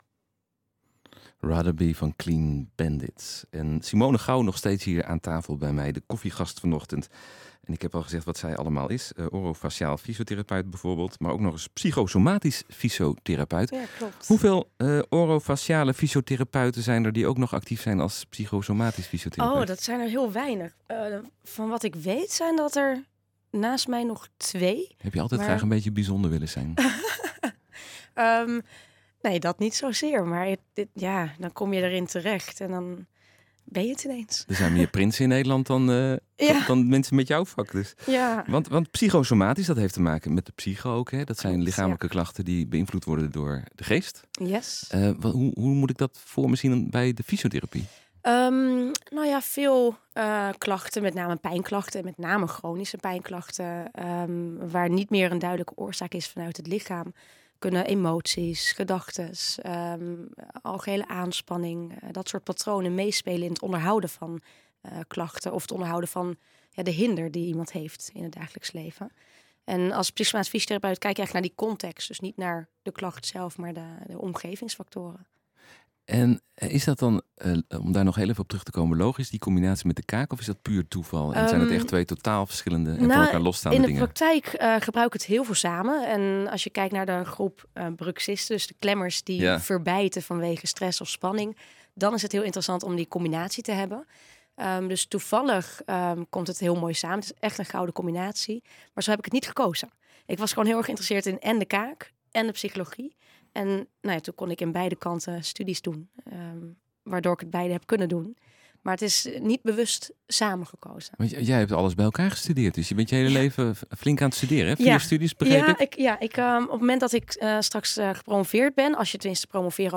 Zij was vandaag  te gast in het radioprogramma ‘Op de koffie’ van omroep Gelderland  naar aanleiding van haar promotie over bruxisme!